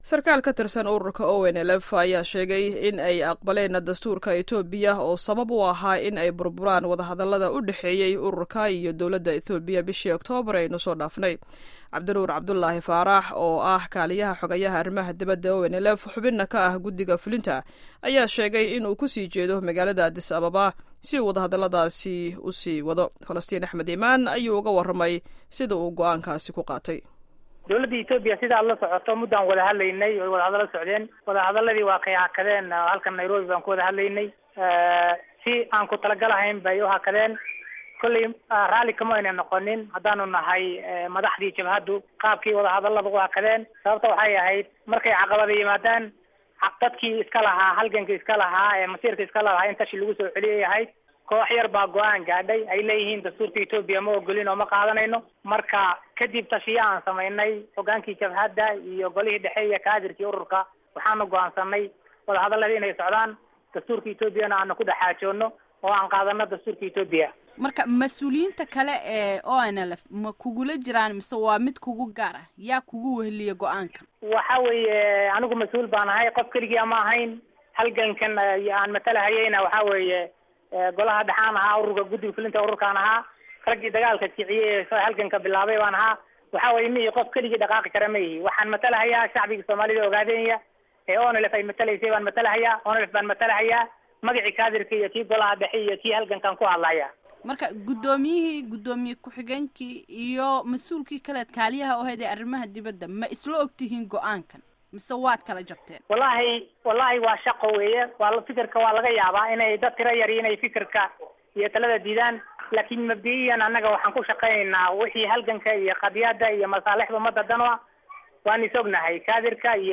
Wareysiyada Mas'uuliyiinta ONLF